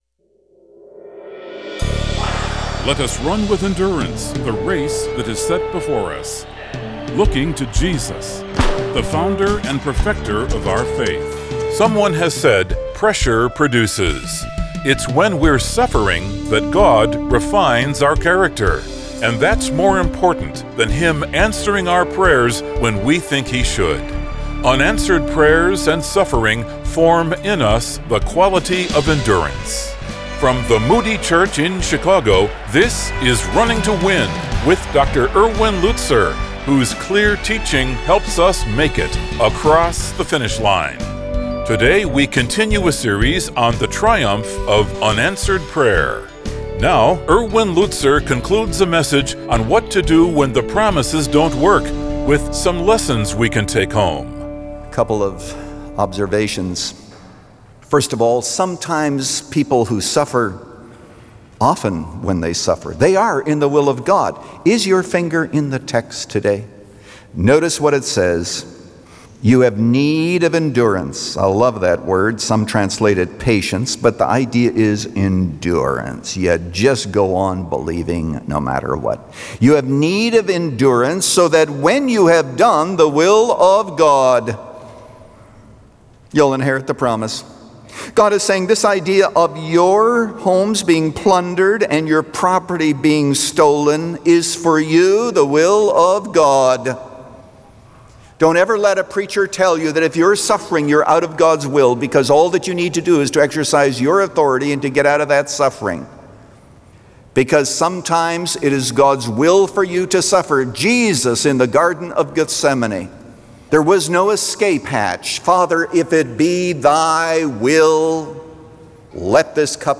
Today this program broadcasts internationally in six languages.